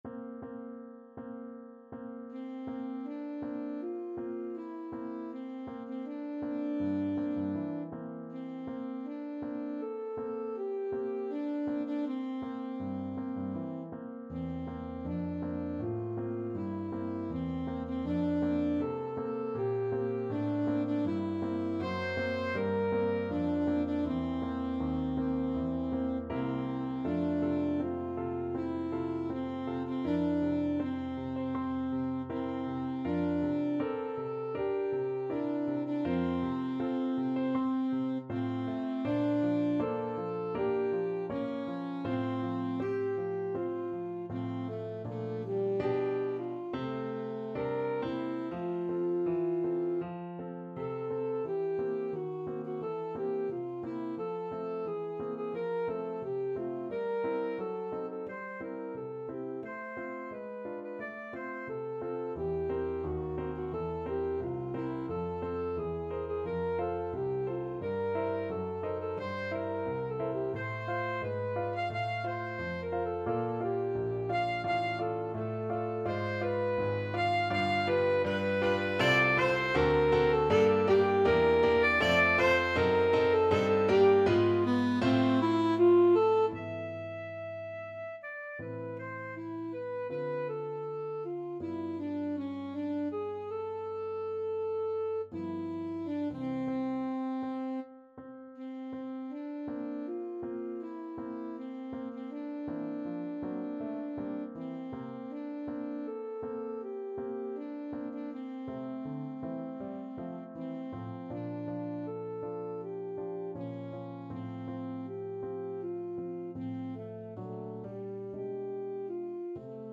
Classical Elgar, Edward Mot d'amour, Op.13. No.1 Alto Saxophone version
Alto Saxophone
F major (Sounding Pitch) D major (Alto Saxophone in Eb) (View more F major Music for Saxophone )
4/4 (View more 4/4 Music)
Andante espressivo
Classical (View more Classical Saxophone Music)